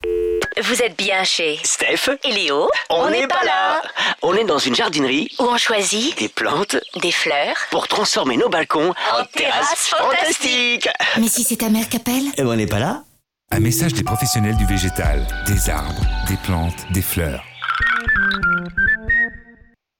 VALHOR_SpotRadio_Avril2015_Terrasse1.mp3